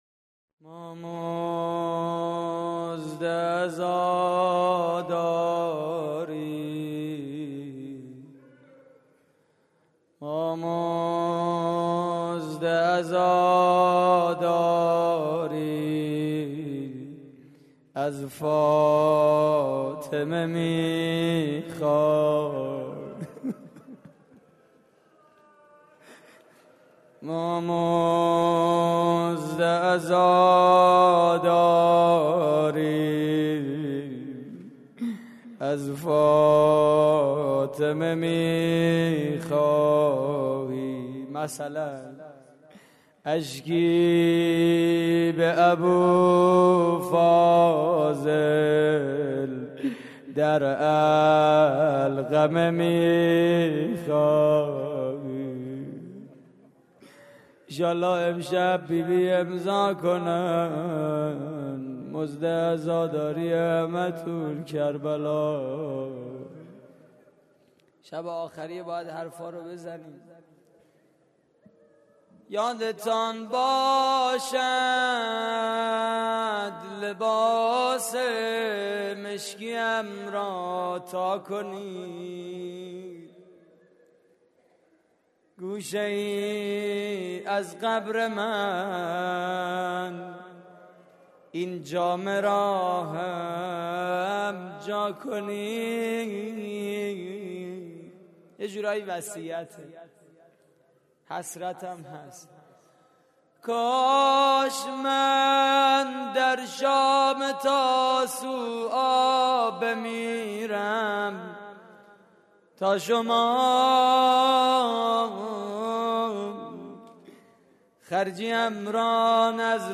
روضه پایانی: یادتان باشد لباس مشکی‌ام را تا کنید
مراسم عزاداری شهادت امام سجاد (ع) / هیئت الزهرا (س) – دانشگاه صنعتی شریف؛